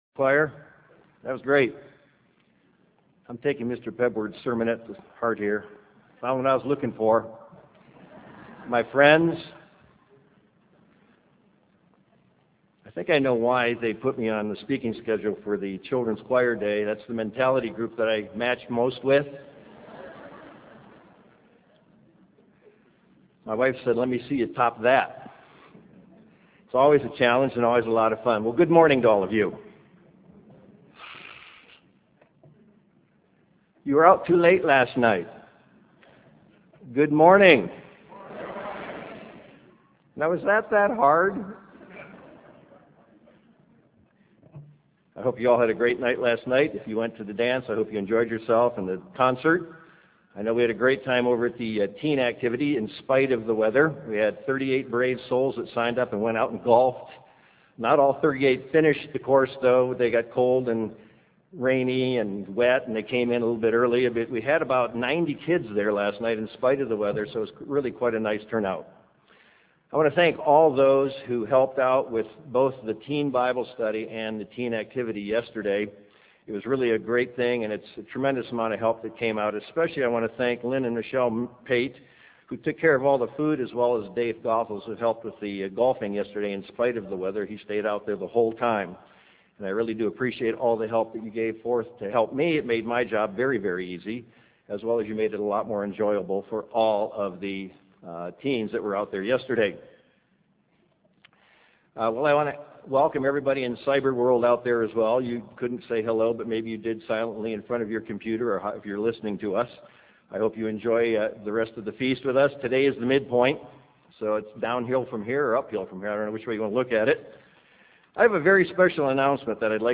This sermon was given at the Bend, Oregon 2007 Feast site.